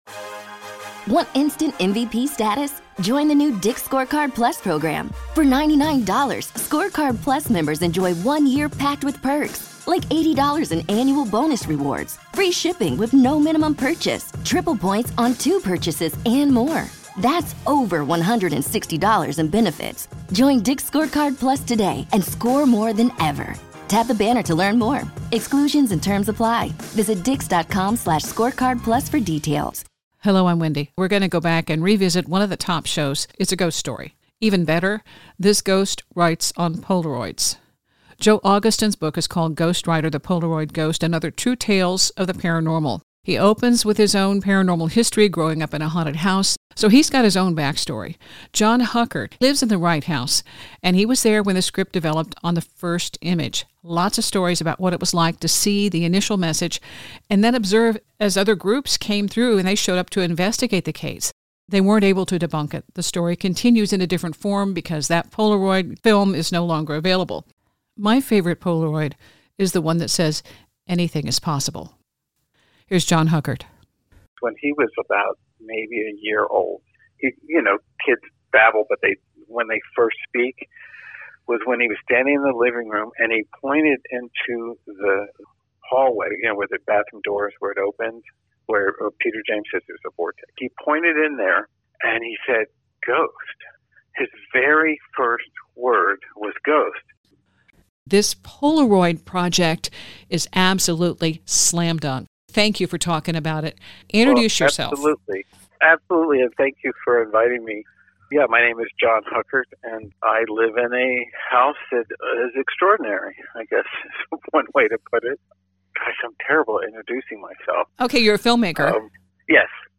*One of the most listened to interviews.